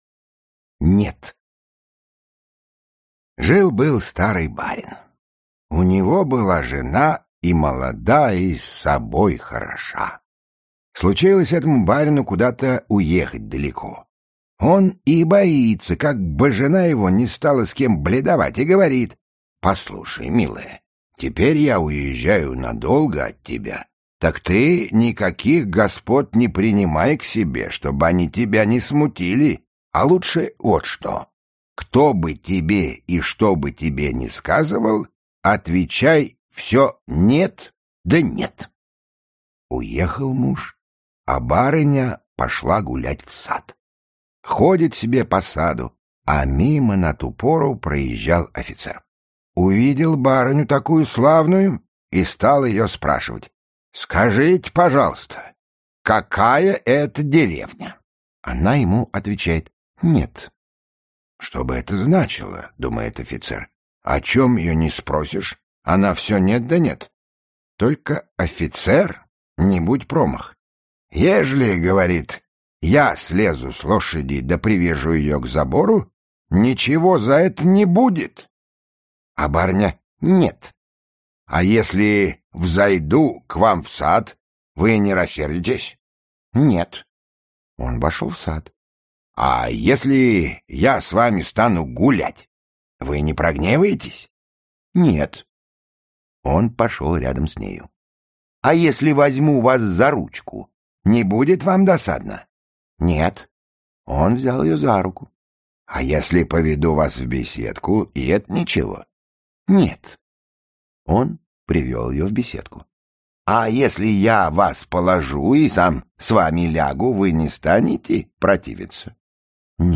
Аудиокнига Заветные сказки | Библиотека аудиокниг
Aудиокнига Заветные сказки Автор Александр Николаевич Афанасьев Читает аудиокнигу Михаил Горевой.